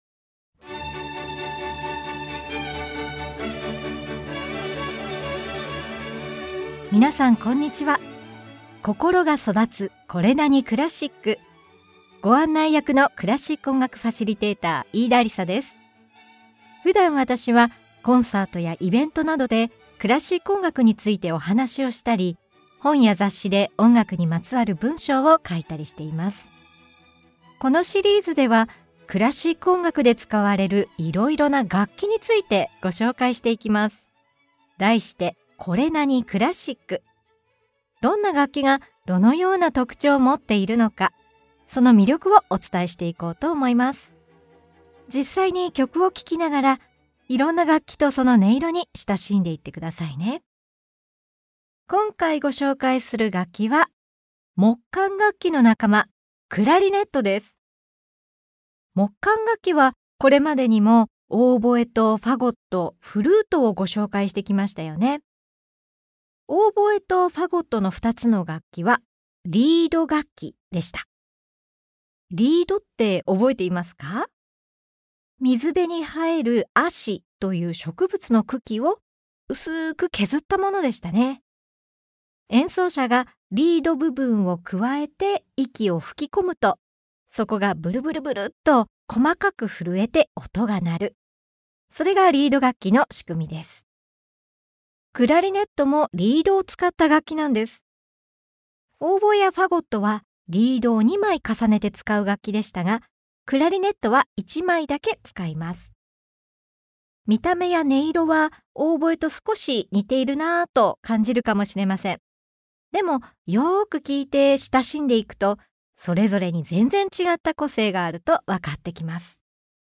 このシリーズでは、どんな楽器がどのような特徴をもっているのか、その魅力をお伝えしていきます。実際に曲を聴きながら、いろんな楽器とその音色に親しんでください。
Vol.9では、木管楽器ならではの柔らかい音色が魅力的な「クラリネット」に注目！
オーボエとクラリネットの聞き比べ